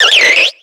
Cri de Ceribou dans Pokémon X et Y.